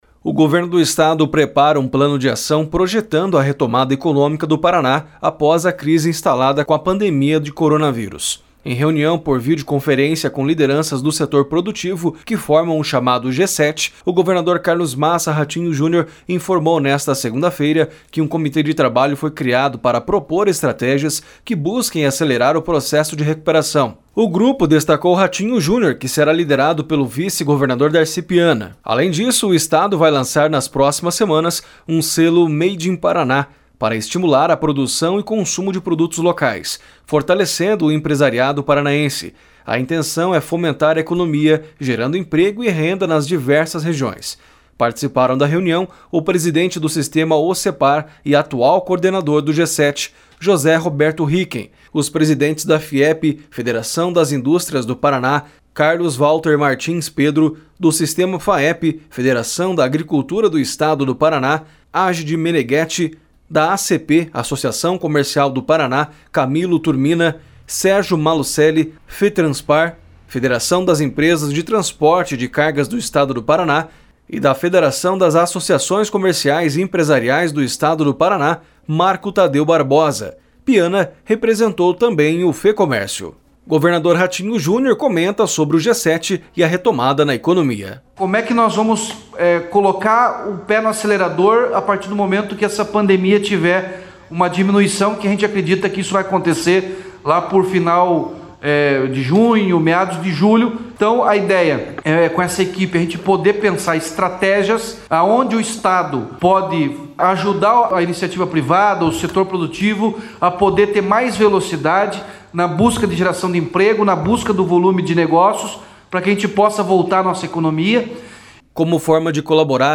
Governador Ratinho Junior comenta sobre o G7 e a retomada na economia.